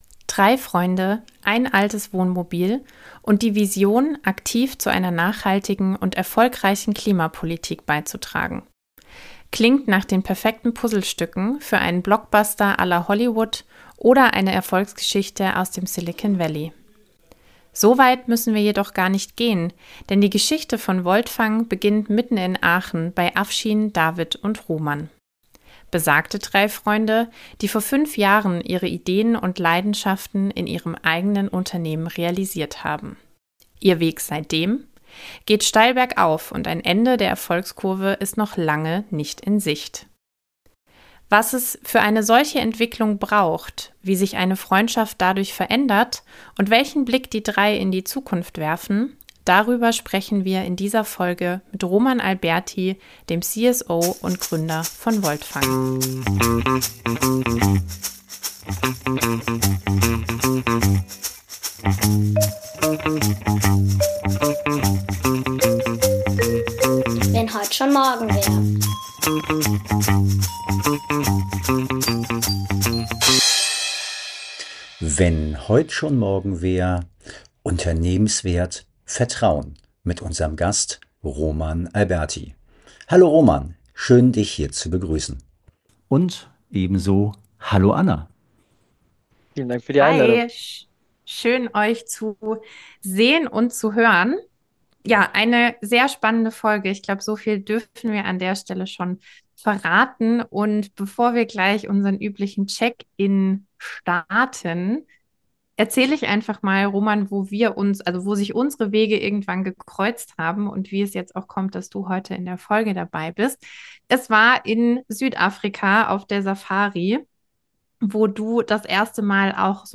Ein zukunftsgewandtes Gespräch